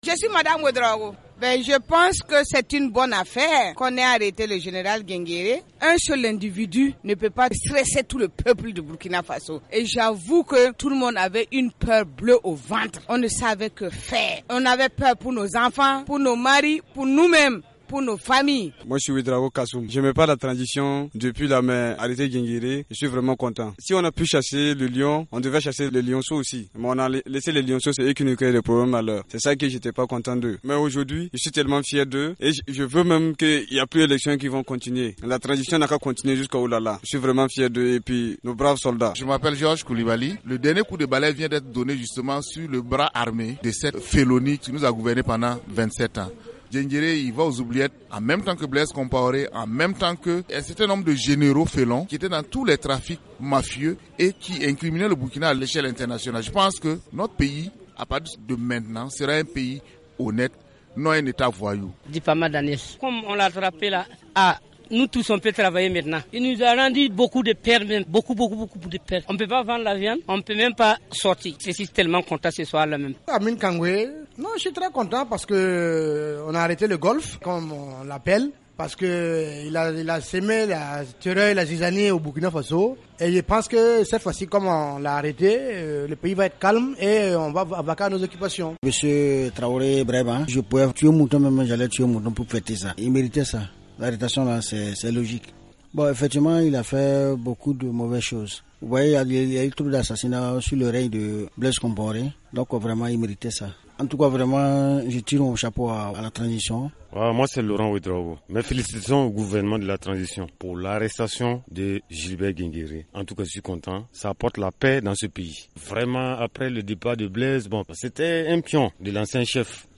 Sentiment partagé par les citoyens interviewés par notre correspondant à Ouagadougou.
Micro-trottoir